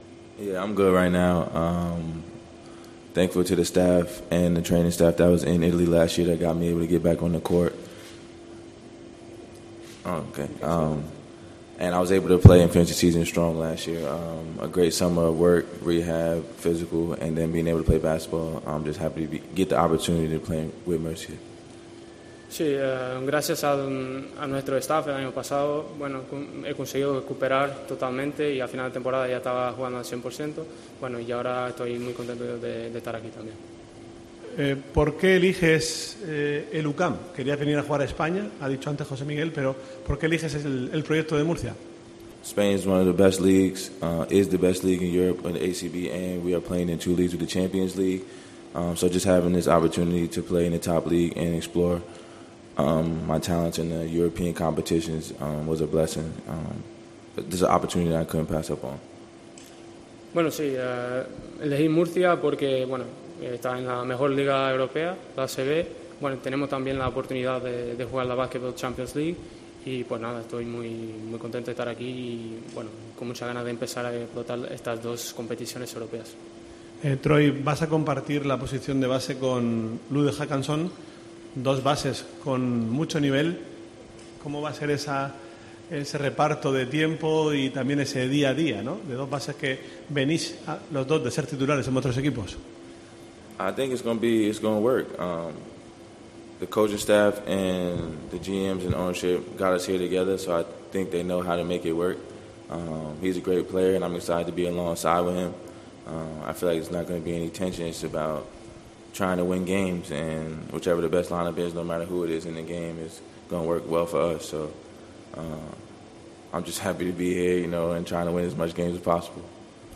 El norteamericano se expresó en esos términos en la sala de catas de la fábrica cervecera Estrella de Levante, a su vez patrocinador del UCAM CB, donde tuvo lugar el acto.